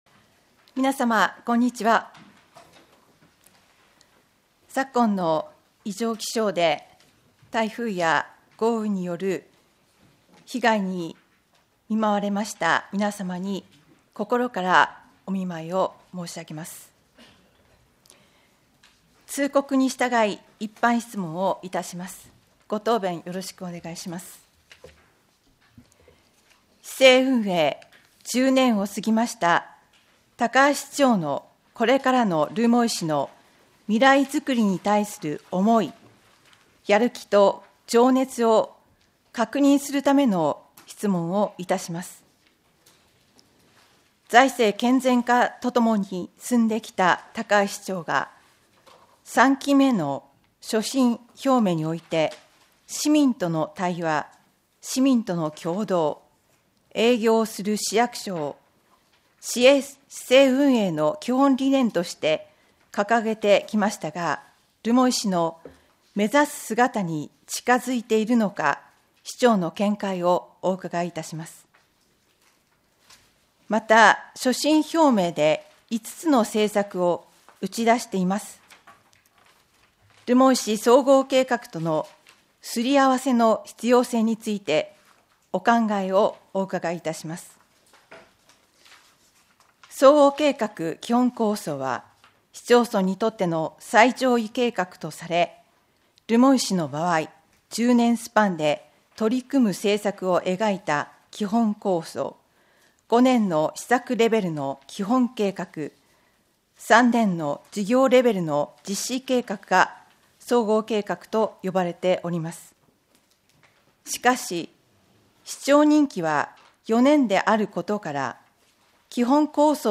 議会録音音声